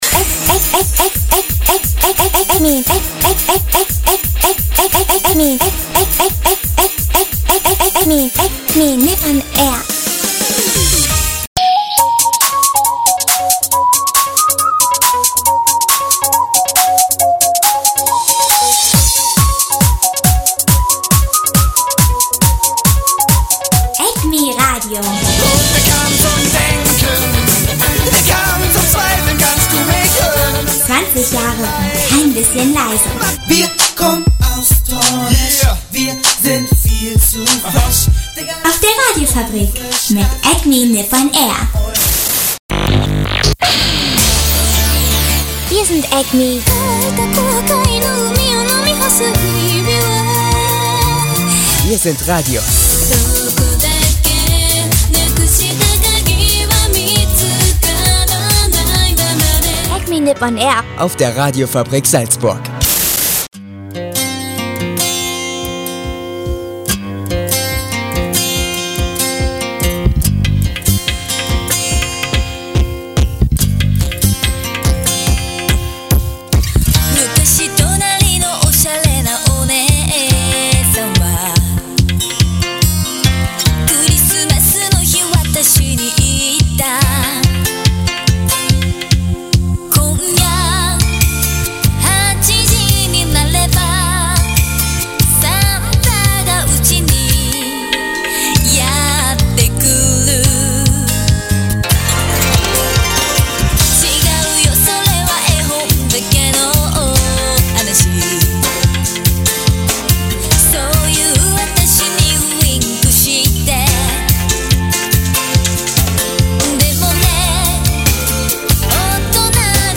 Es ist Weihnachtszeit, aber trotzdem gibts natürlich eine (aufgezeichnete) Acme.Nipp-on-AiR-Folge. Mit viel Weihnachtsmusik, so dass ihr euren Eltern mal zeigen könnt, dass es nicht nur immer „Last Christmas“ sein muss.